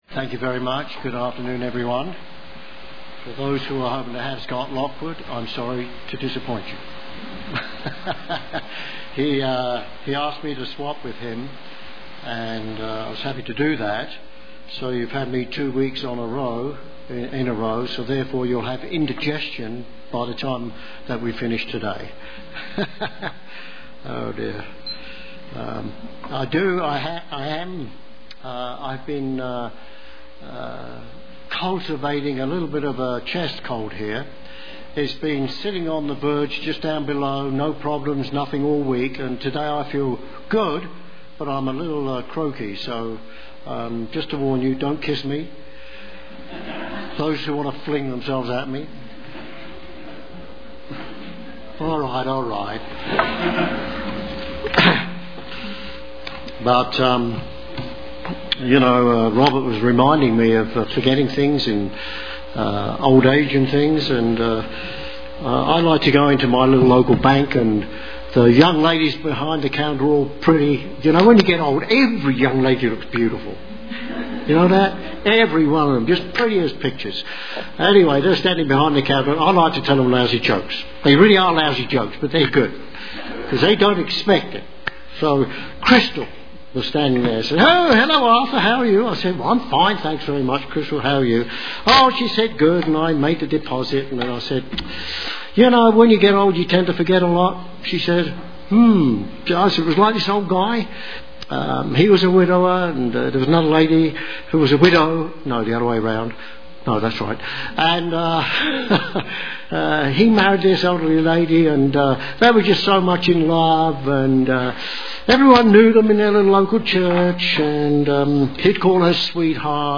This sermon was based on the famous Antarctic explorer, Sir Ernest Shackleton.
Given in Colorado Springs, CO